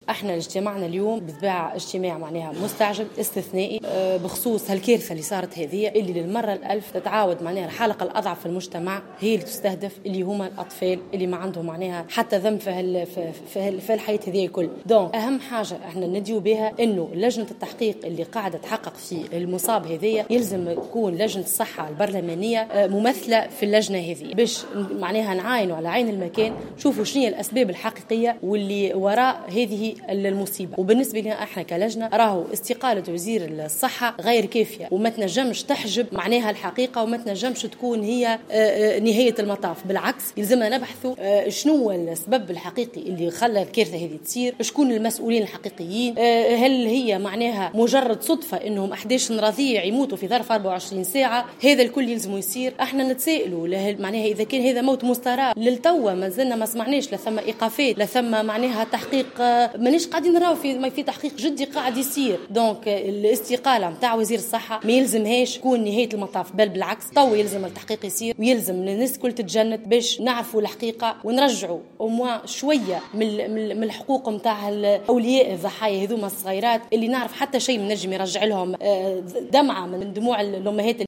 أكدت مقررة لجنة الصحة صلب البرلمان أميرة زوكاري في تصريح لمراسل الجوهرة "اف ام" خلال ندوة صحفية اليوم الإثنين أن اللجنة طالبت بأن تكون ممثلة في لجنة التحقيق الخاصة بوفاة الرضع بمستشفى الرابطة بالعاصمة.